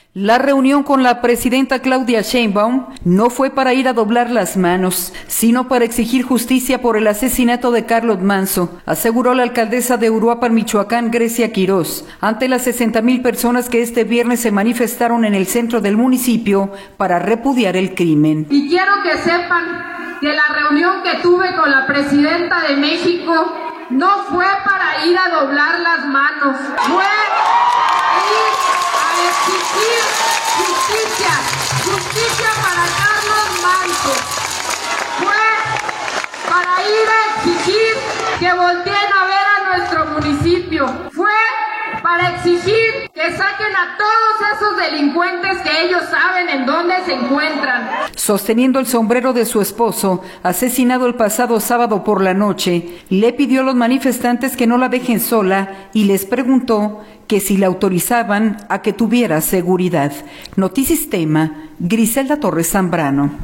La reunión con la presidenta Claudia Sheinbaum no fue para ir a doblar las manos, sino para exigir justicia por el asesinato de Carlos Manzo, aseguró la alcaldesa de Uruapan, Michoacán, Grecia Quiroz, ante las 60 mil personas que este viernes se manifestaron en el centro del municipio para repudiar el crimen.